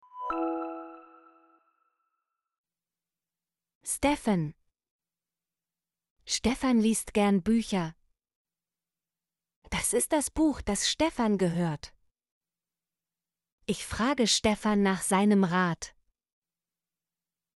stefan - Example Sentences & Pronunciation, German Frequency List